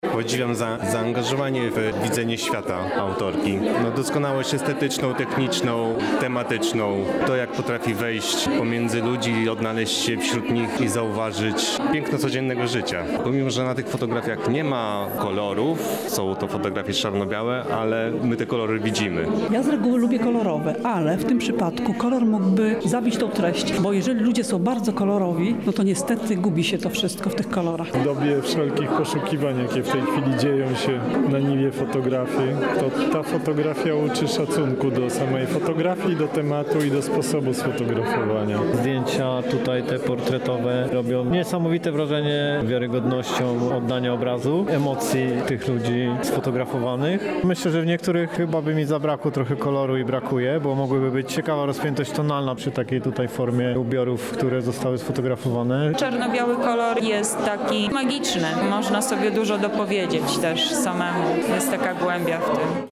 Relacja, „Człowiek w teatrze życia codziennego”